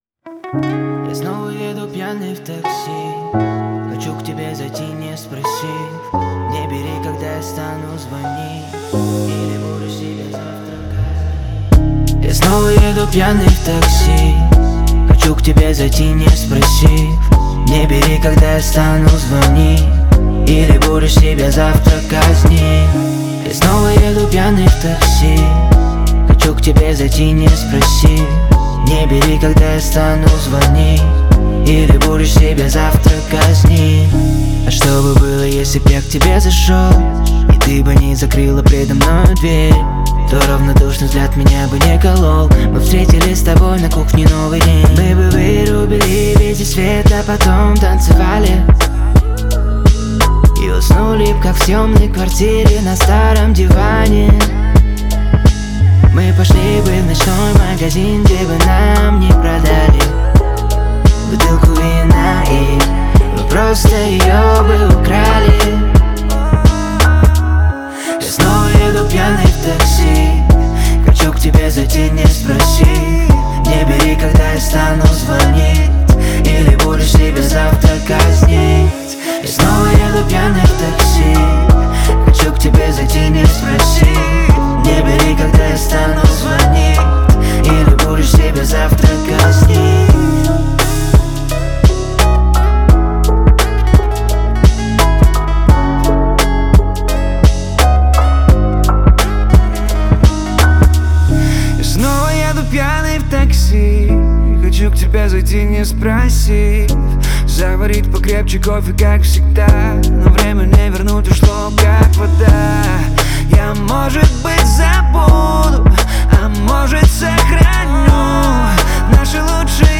это яркая и запоминающаяся композиция в жанре поп